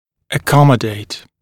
[ə’kɔmədeɪt][э’комэдэйт]вмещать, размещать (напр. о зубе в зубной дуге)